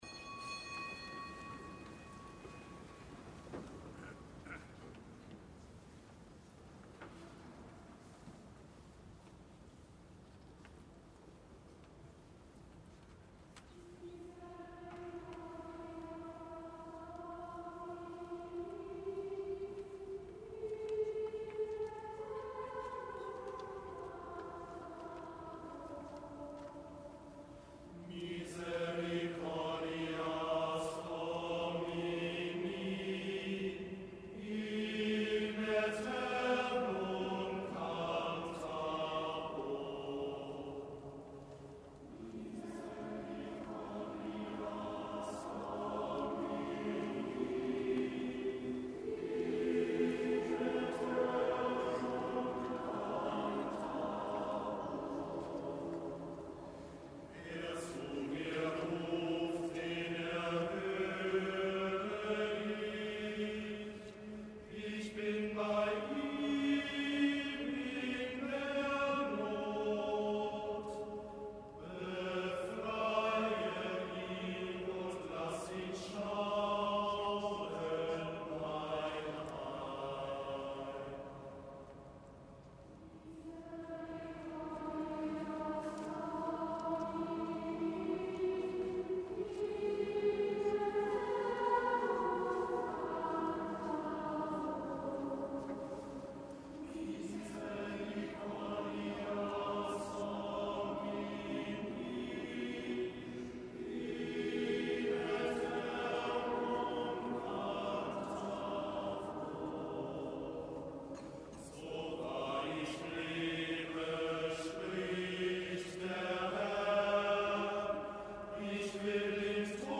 Kapitelsamt am ersten Fastensonntag